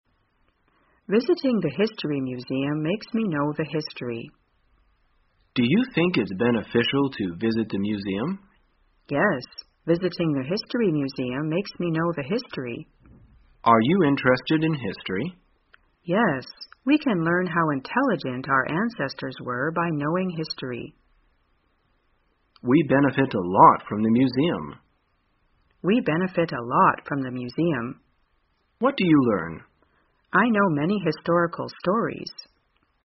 在线英语听力室生活口语天天说 第285期:怎样谈论历史的听力文件下载,《生活口语天天说》栏目将日常生活中最常用到的口语句型进行收集和重点讲解。真人发音配字幕帮助英语爱好者们练习听力并进行口语跟读。